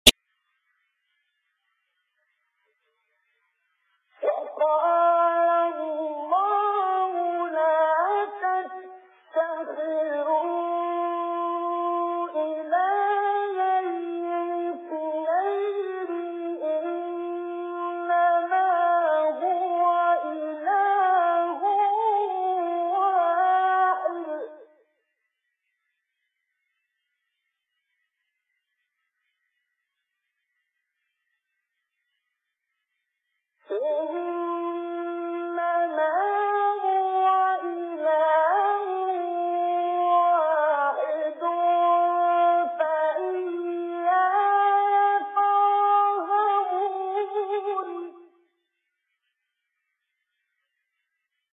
گروه شبکه اجتماعی: فرازهایی از تلاوت قاریان بنام کشور مصر ار جمله شیخ رفعت، احمد صالح، عبدالفتاح شعشاعی را می‌شنوید.
فرازی استودیویی از عبدالباسط محمد عبدالصمد/ سوره مبارکه نحل